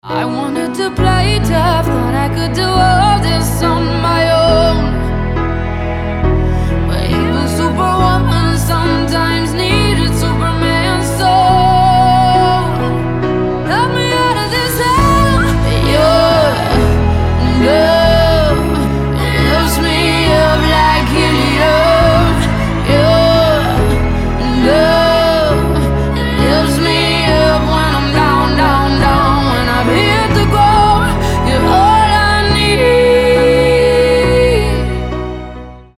• Качество: 256, Stereo
красивые
медленные